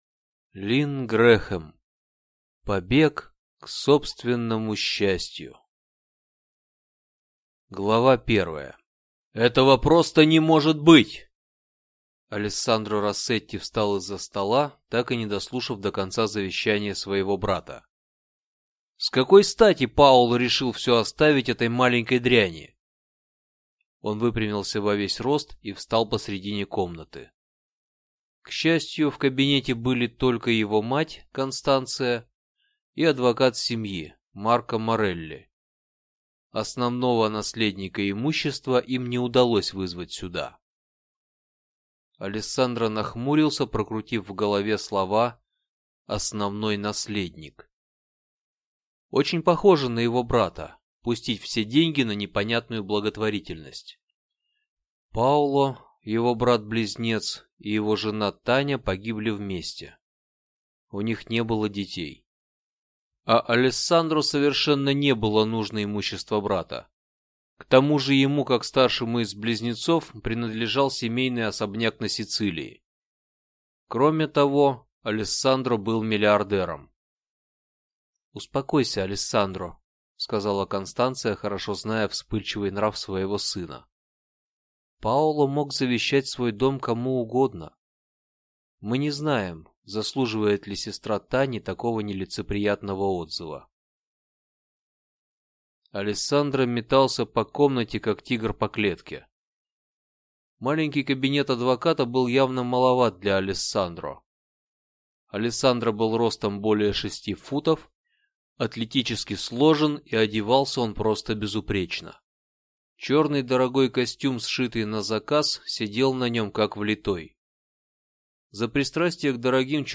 Аудиокнига Побег к собственному счастью | Библиотека аудиокниг
Aудиокнига Побег к собственному счастью Автор Линн Грэхем